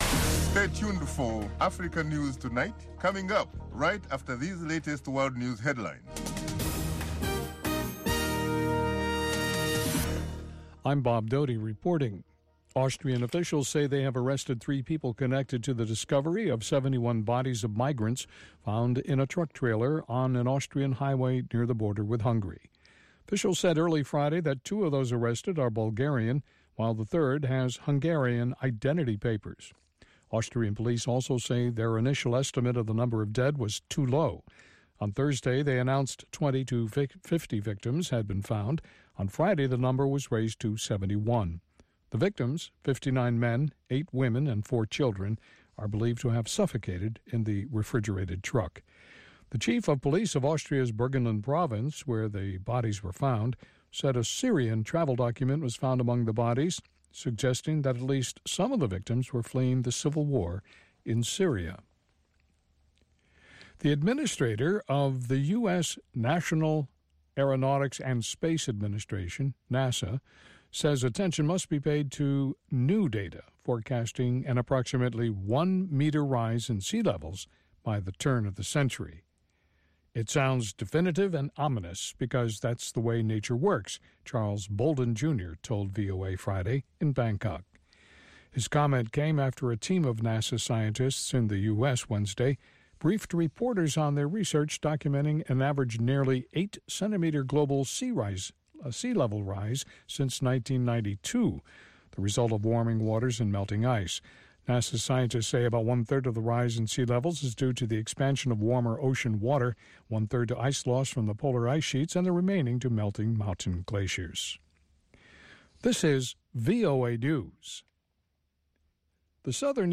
Africa News Tonight is a lively news magazine show featuring VOA correspondent reports, interviews with African officials, opposition leaders, NGOs and human rights activists.
Music and the popular sports segment, Sonny Side of Sports, round out the show.